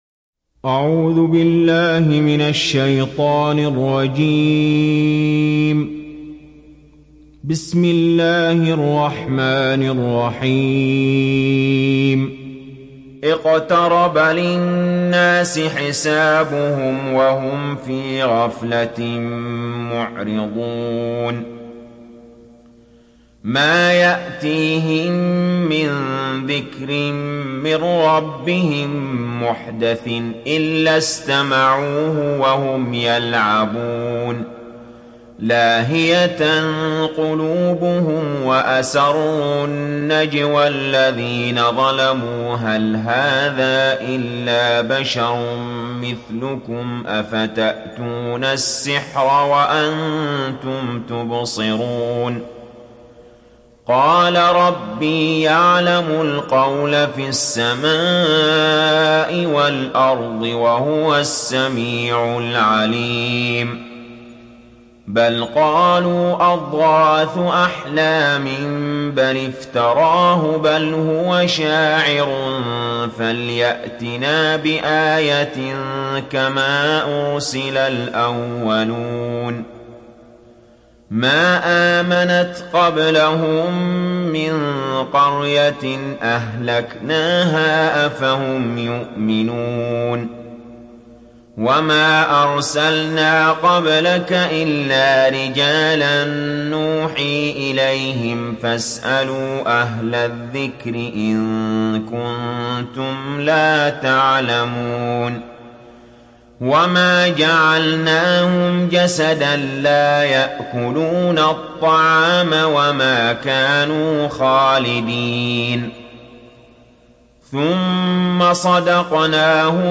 Enbiya Suresi İndir mp3 Ali Jaber Riwayat Hafs an Asim, Kurani indirin ve mp3 tam doğrudan bağlantılar dinle